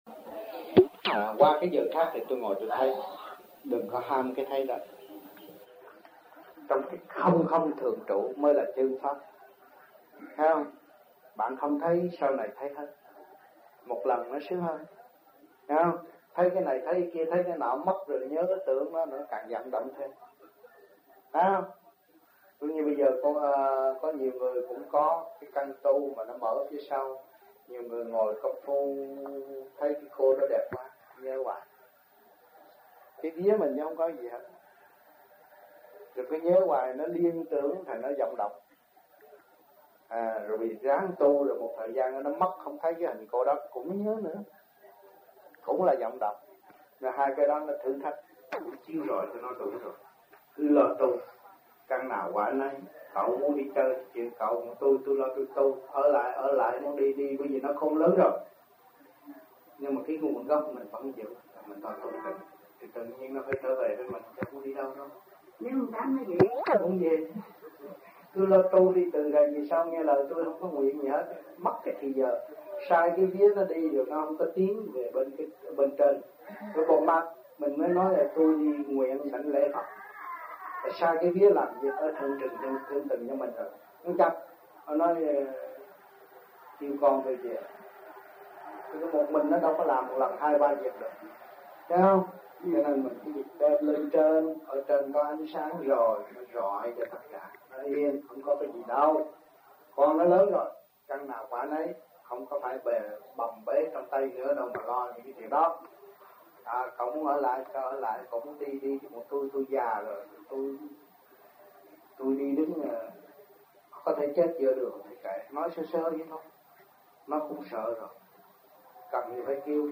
1974 Đàm Đạo